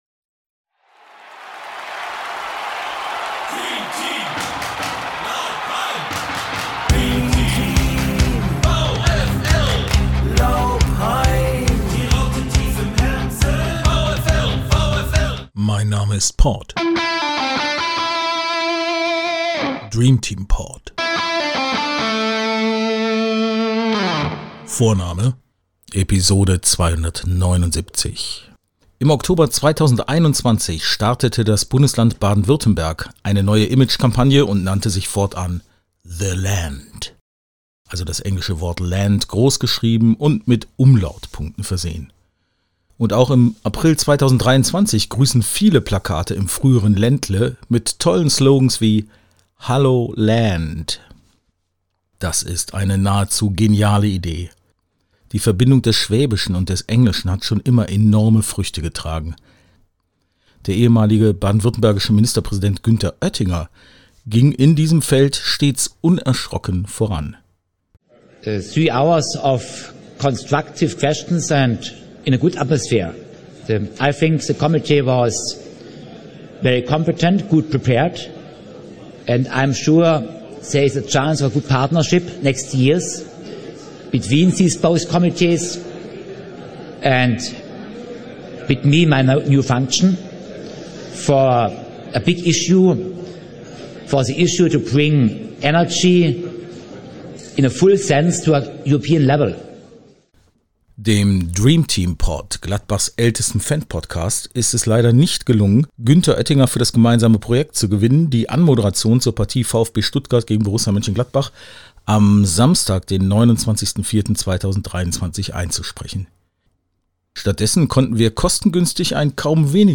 Daher gibt es heute, zur Partie des VfB Stuttgart gegen die Borussia am 29.4.2023, einen Vorbericht im Stil von The Länd.